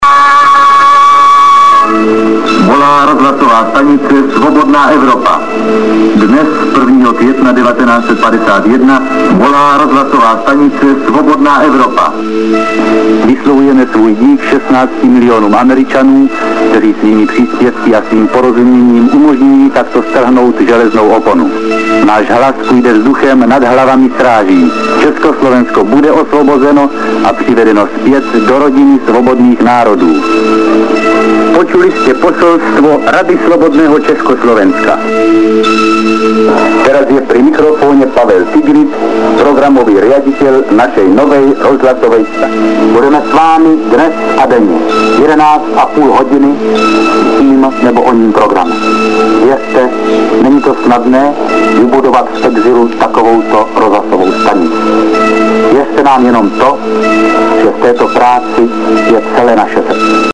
zvuková ukázka prvního vysílání RSE 1.5.1951